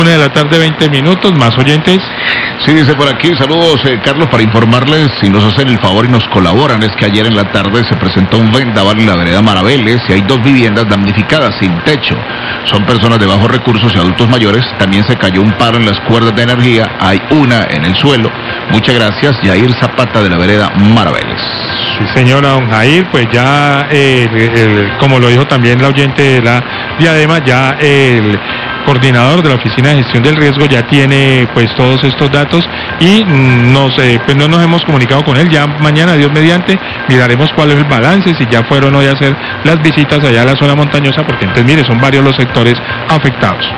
Radio
oyentes